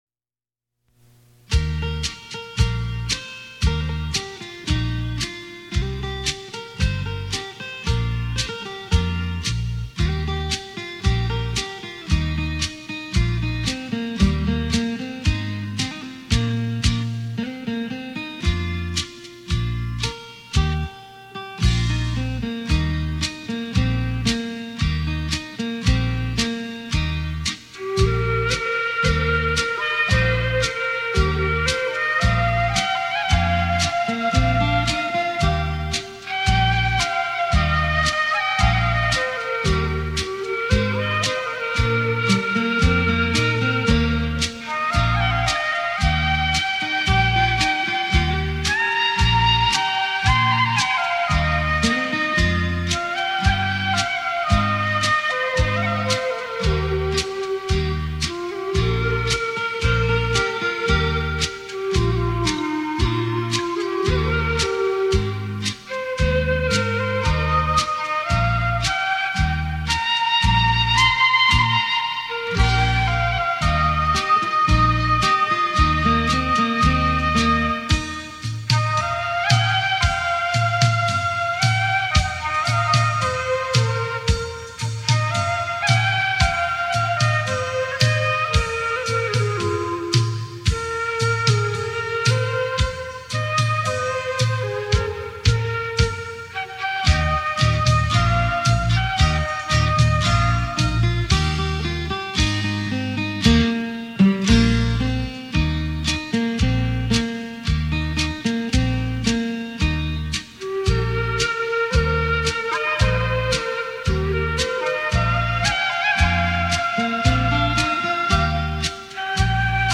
用洞箫悠扬的音色带您回味这曲曲脍炙人口扣人心弦的经典好歌。